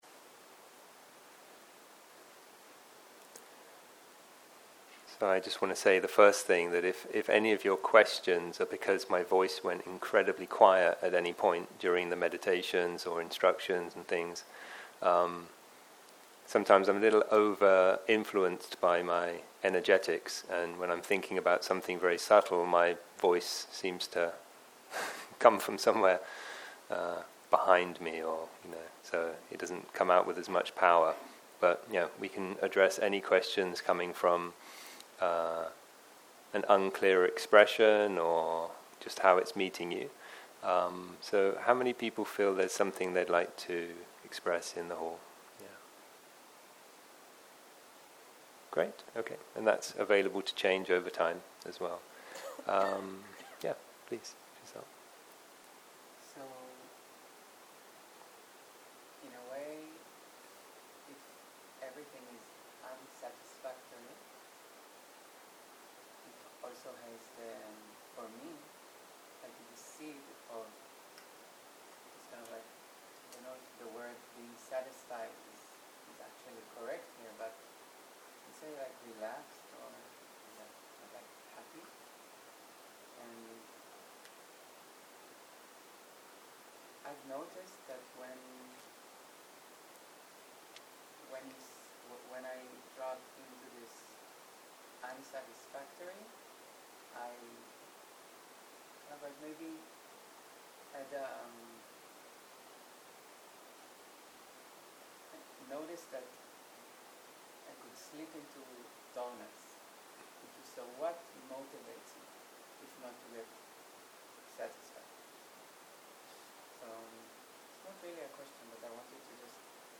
בוקר - שאלות ותשובות
סוג ההקלטה: שאלות ותשובות